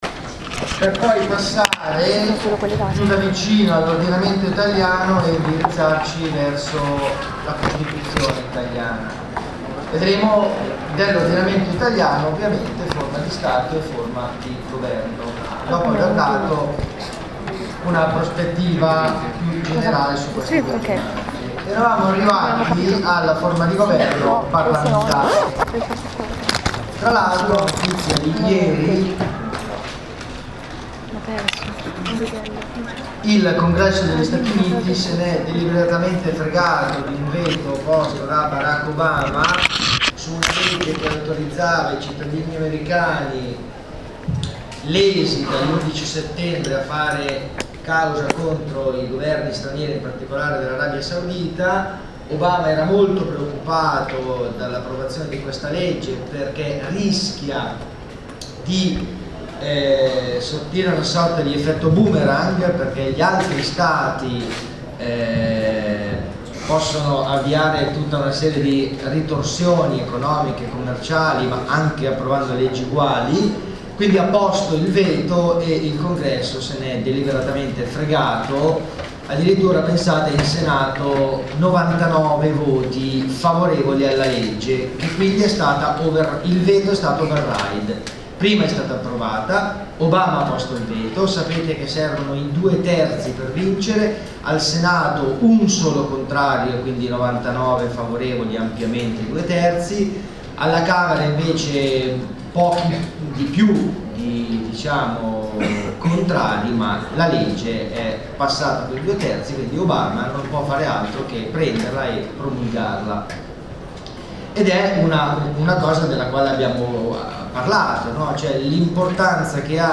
Qui sotto le audio-video registrazioni delle lezioni: